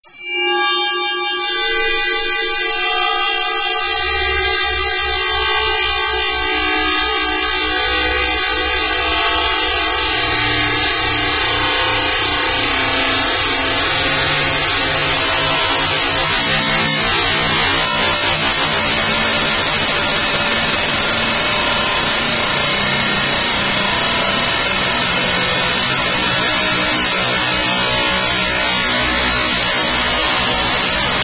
fractalSound1.mp3